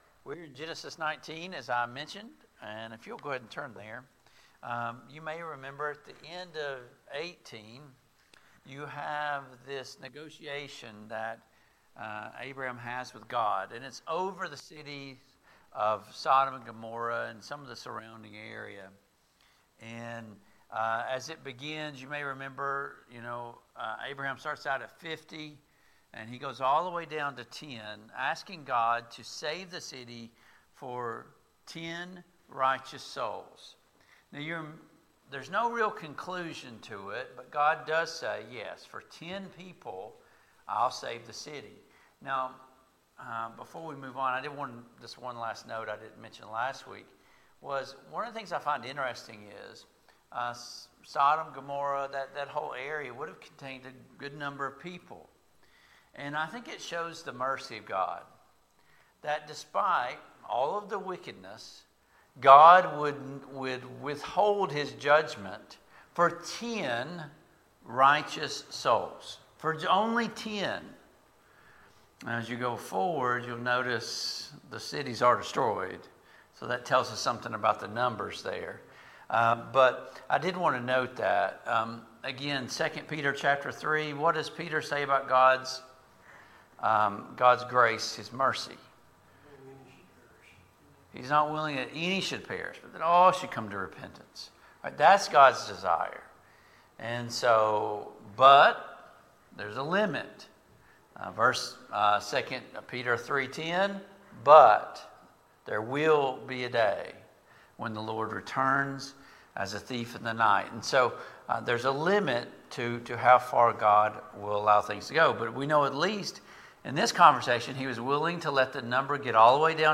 Genesis 19:1-14 Service Type: Family Bible Hour Topics: Sodom and Gomorrah « Six People God cannot Save! 13.